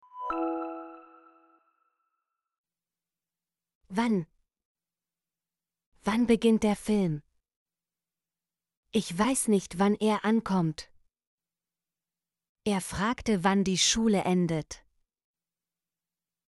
wann - Example Sentences & Pronunciation, German Frequency List